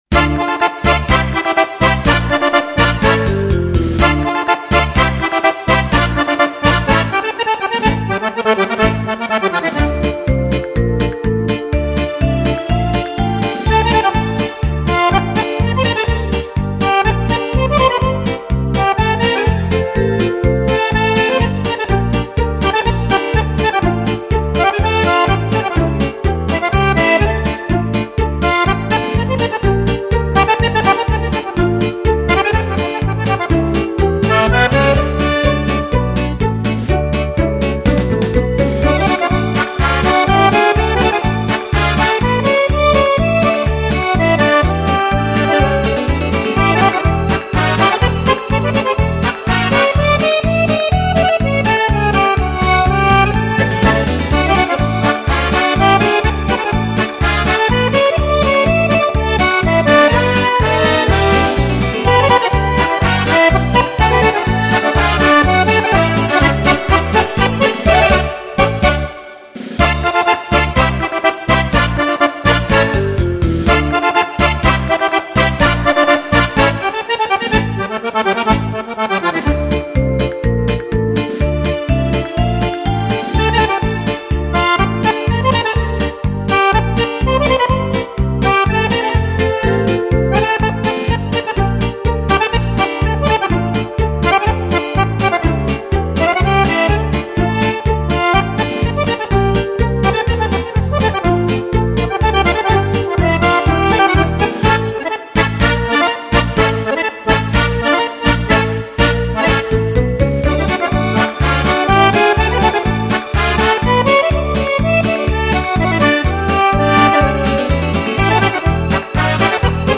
Passo doppio
Compilation di brani per fisarmonica